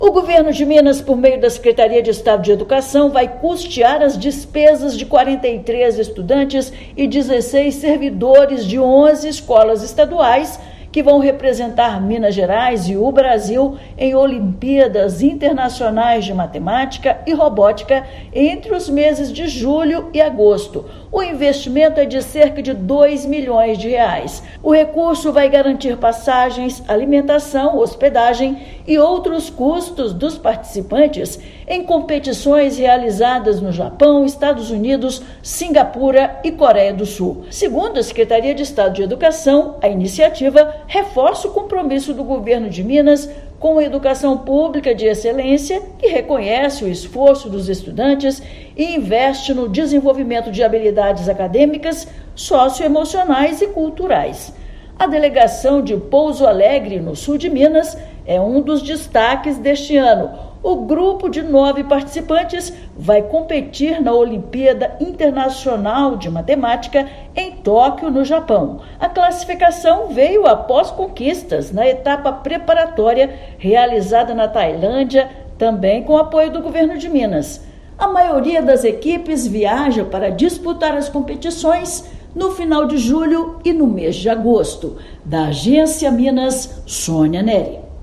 Com investimento de R$ 2 milhões, o maior desta gestão, alunos e professores participarão de competições no Japão, Estados Unidos, Singapura e Coreia do Sul. Ouça matéria de rádio.